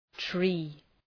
Προφορά
{tri:}